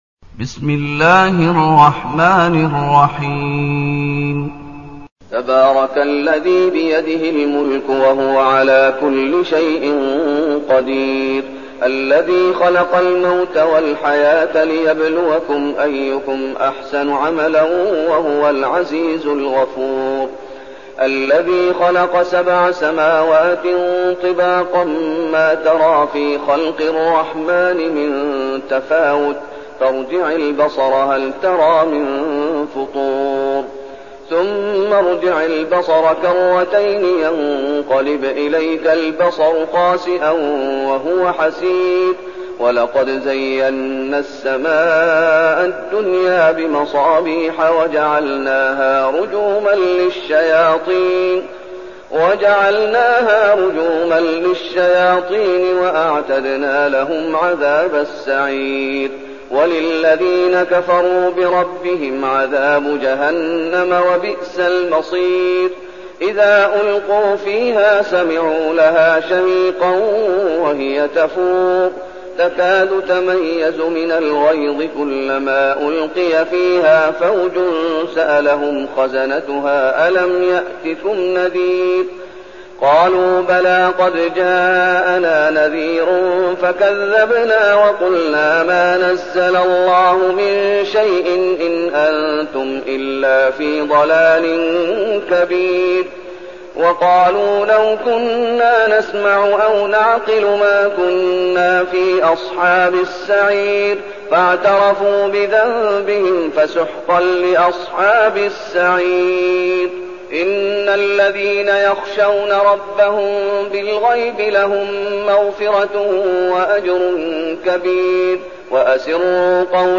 المكان: المسجد النبوي الشيخ: فضيلة الشيخ محمد أيوب فضيلة الشيخ محمد أيوب الملك